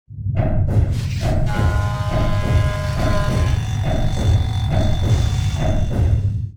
repair1.wav